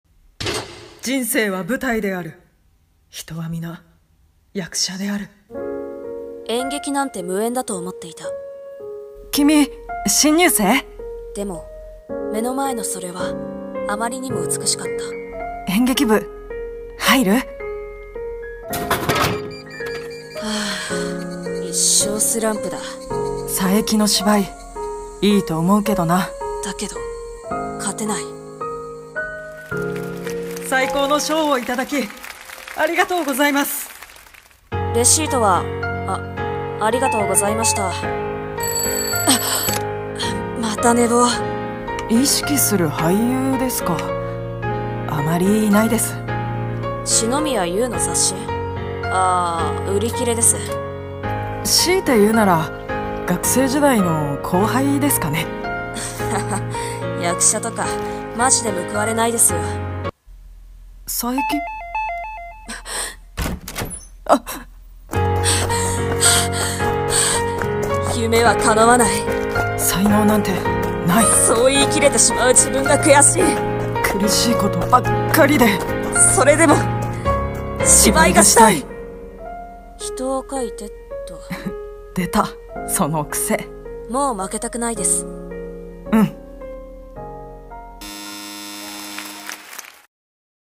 【声劇】Action！